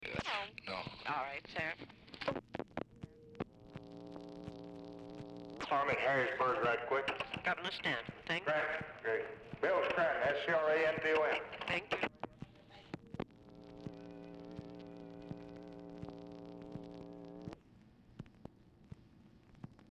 Telephone conversation # 5514, sound recording, LBJ and TELEPHONE OPERATOR, 9/7/1964, time unknown | Discover LBJ
Format Dictation belt
Location Of Speaker 1 Oval Office or unknown location